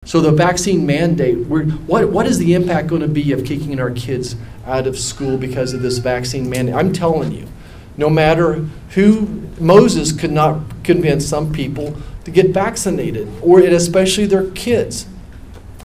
Senator Roger Marshall spoke to members of the community during his town hall over the weekend.